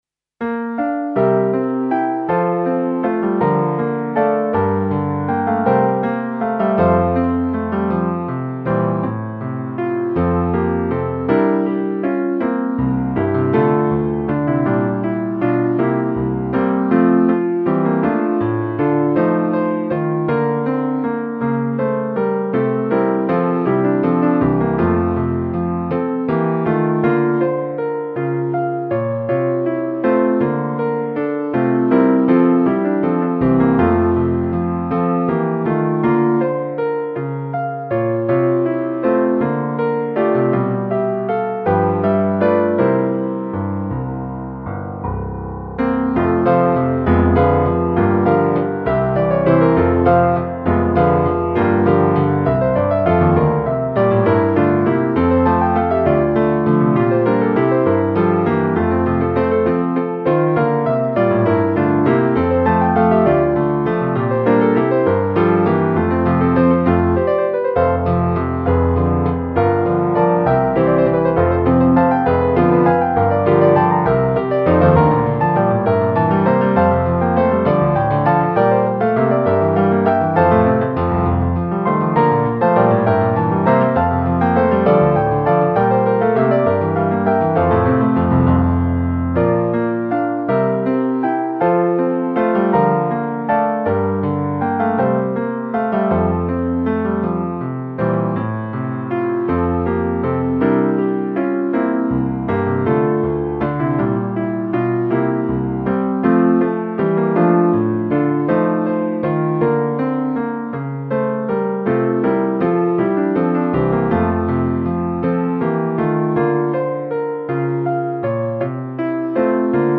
(Base Mp3)
sognando pinocchio base.mp3